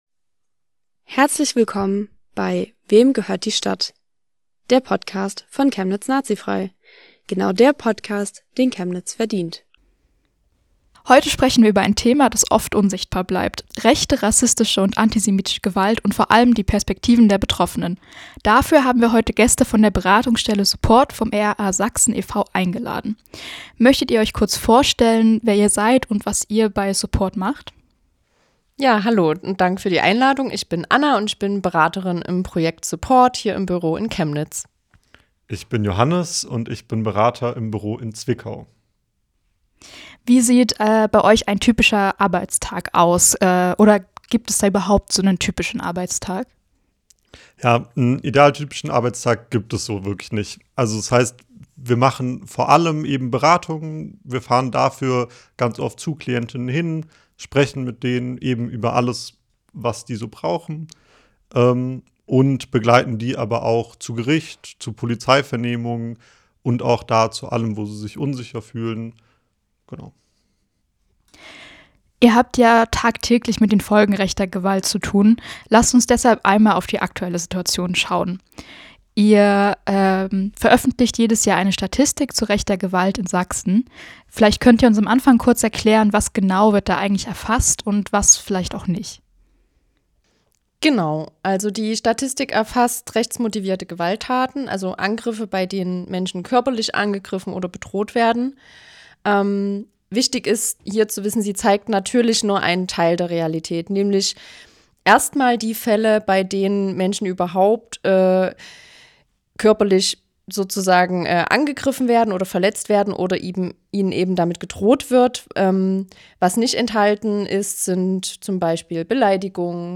In dieser Folge sprechen wir mit zwei Berater*innen der Opferberatungsstelle SUPPORT. Sie begleiten Betroffene nach rechten Angriffen und geben Einblicke in eine Realität, die für viele unsichtbar bleibt – aber den Alltag vieler Menschen prägt. Wir schauen darauf, wie junge rechte Gruppen versuchen, Räume einzunehmen und Angst zu verbreiten.